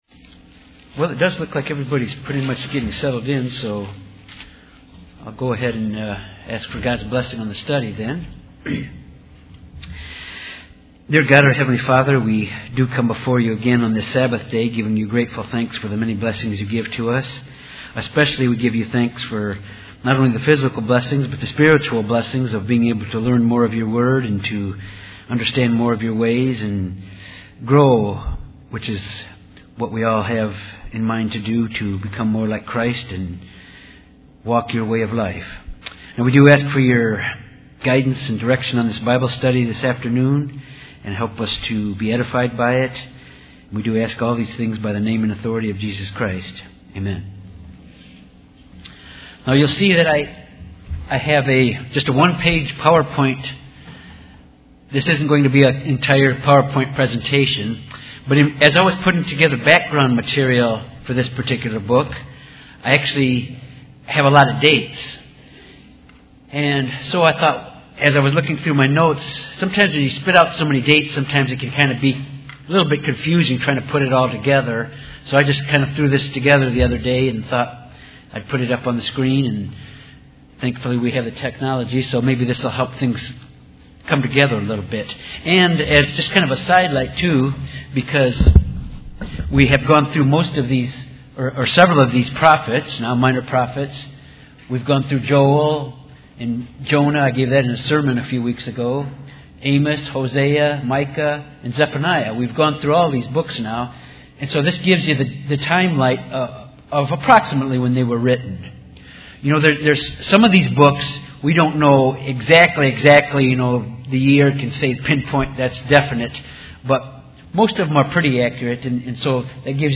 Bible Study on Habakkuk, background and chapter one. Habakkuk questions God for using the Babylonians to punish Judah. God answers that the Babylonians will be punished as well.